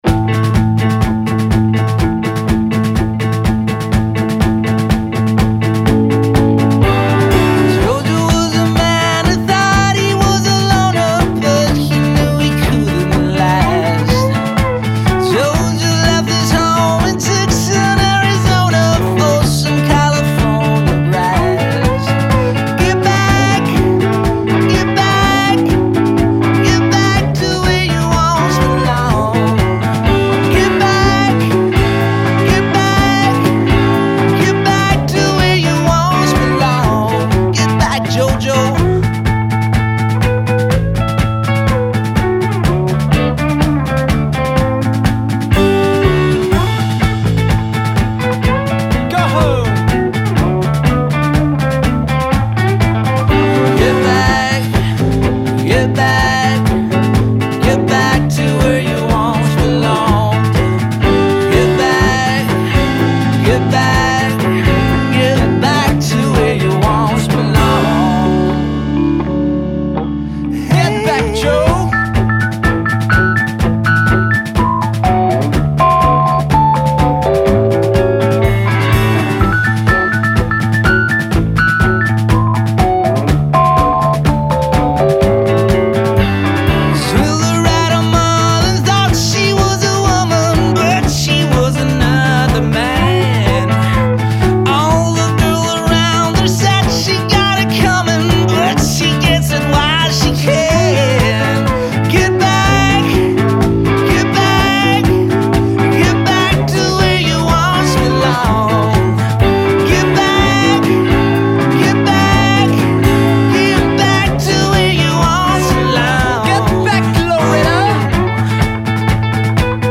Genere: Rock.